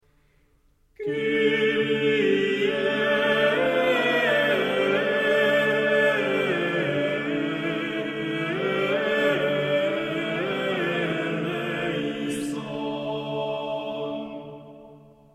polyph.mp3